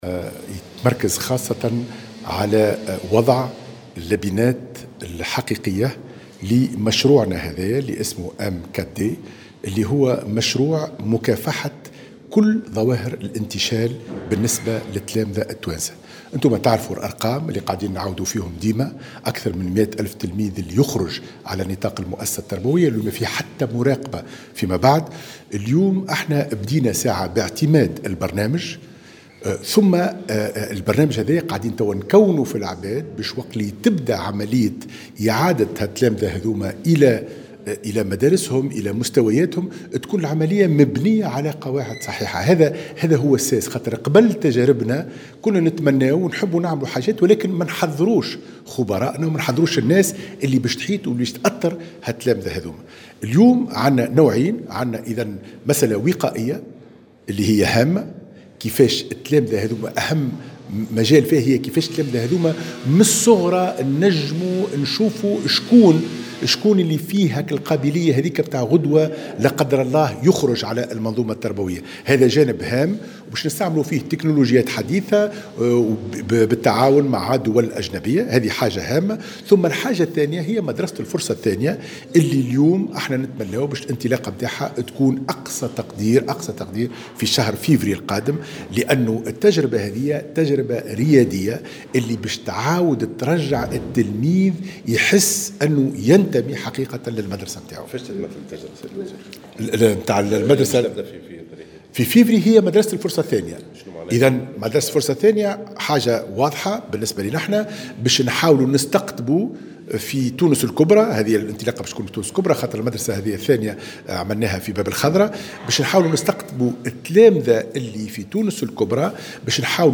أكد وزير التربية حاتم بن سالم في تصريح لمراسلة "الجوهرة اف أم" اليوم أنه سيتم اطلاق بداية من شهر فيفري المقبل اطلاق مدرسة الفرصة الثانية لإعادة التلاميذ إلى مقاعد الدراسة.
وجاءت هذه التصريحات على هامش افتتاح تظاهرة تكوينية حول آليات تنفيذ مشروع "ام كات دي" في الحمامات.